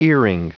Prononciation du mot earring en anglais (fichier audio)
Prononciation du mot : earring